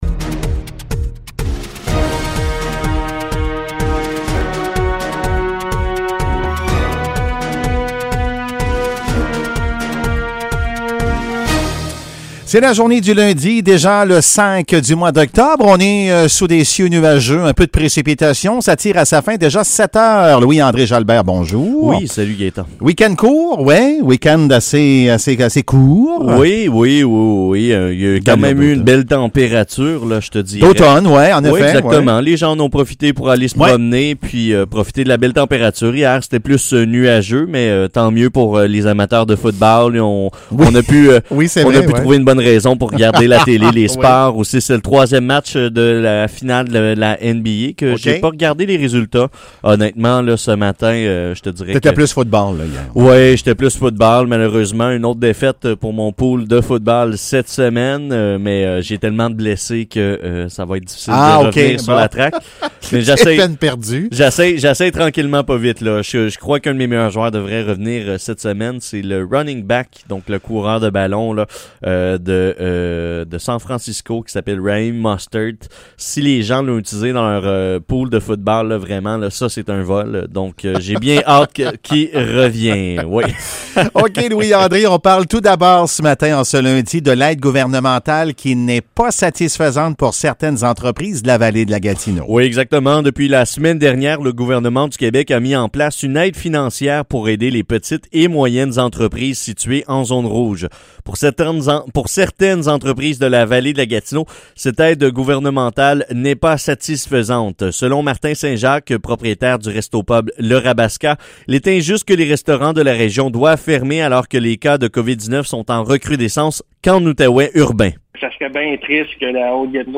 Nouvelles locales - 5 octobre 2020 - 7 h